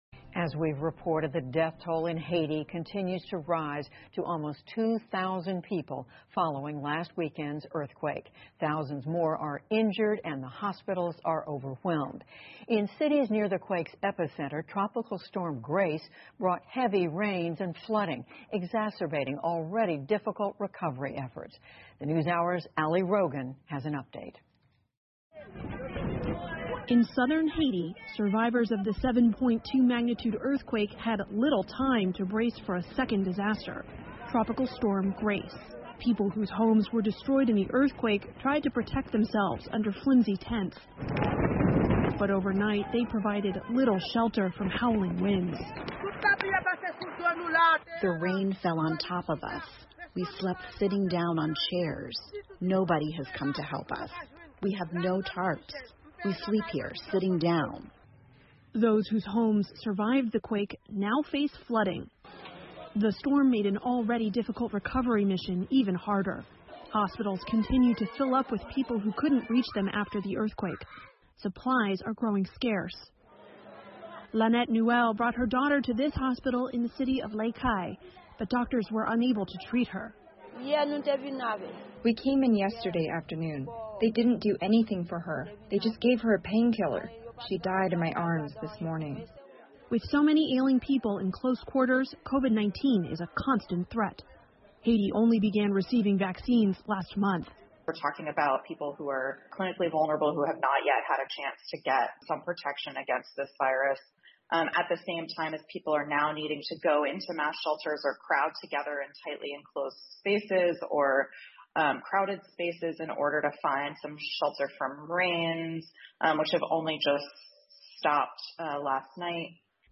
PBS高端访谈:海地受"地震""洪水""新冠"三面夹击,海地人民水深火热 听力文件下载—在线英语听力室